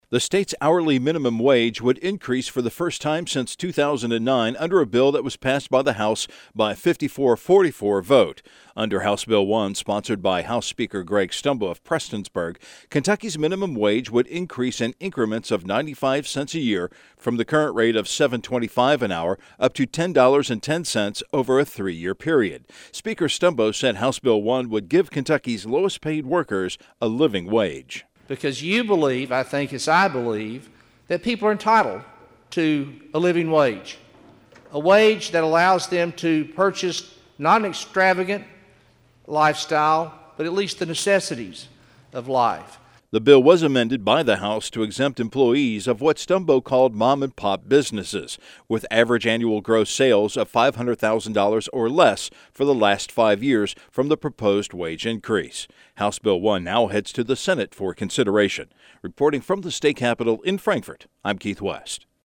Click here to listen to House Speaker Greg Stumbo discuss HB 1, which will raise the minimum wage to $10.10 by 2016.